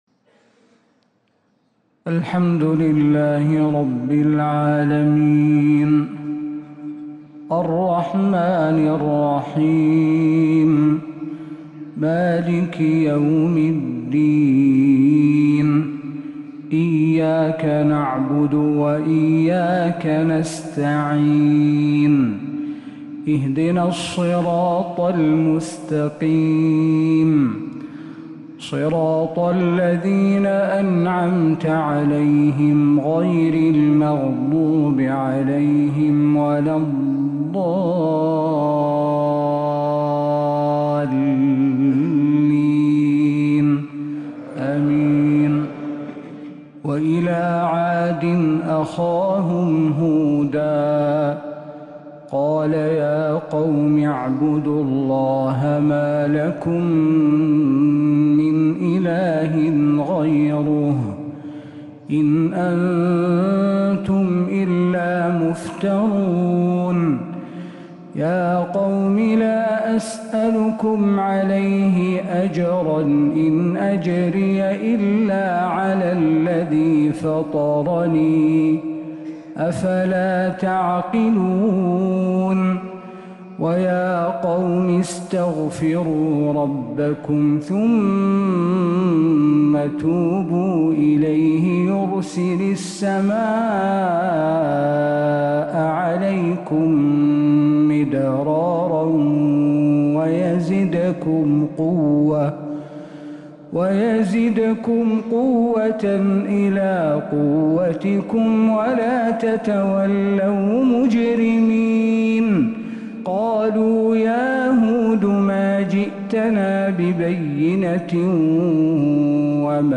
الإصدارات الشهرية لتلاوات الحرم النبوي 🕌 ( مميز )